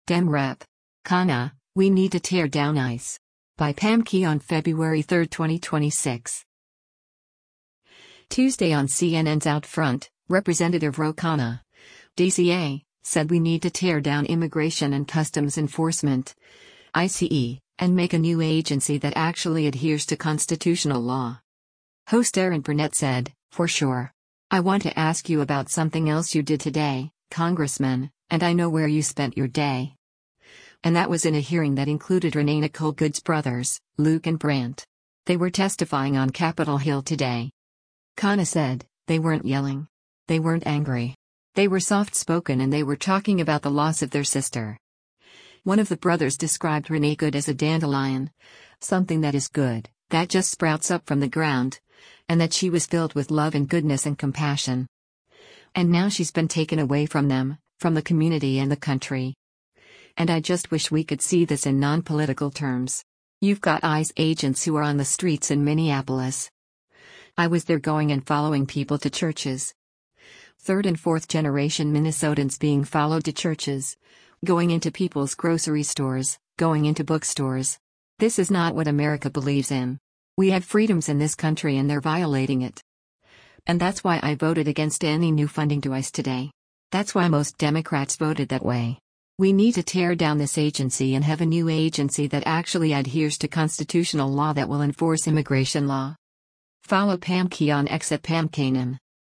Tuesday on CNN’s “OutFront,” Rep. Ro Khanna (D-CA) said “we need to tear down” Immigration and Customs Enforcement (ICE) and make a “new agency that actually adheres to constitutional law.”